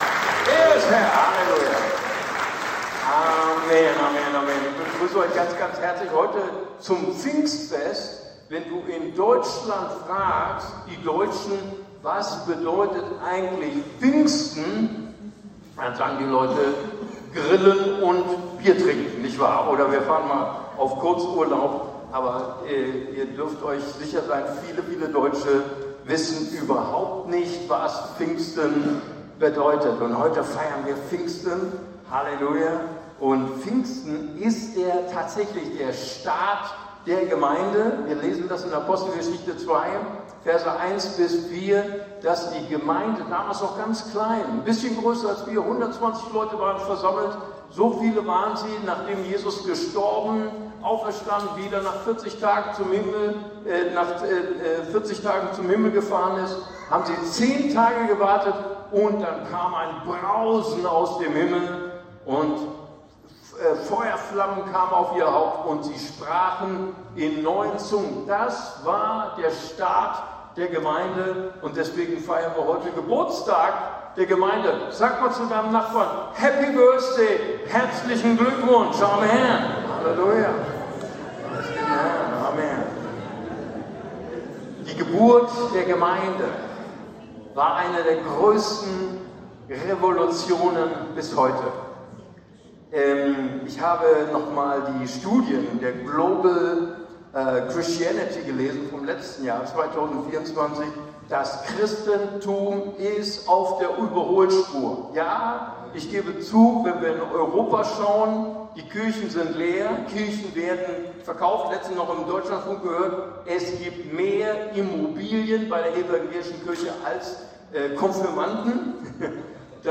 ICB Predigtreihe Römerbrief Teil 12